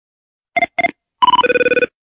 • Качество: 64, Stereo
короткие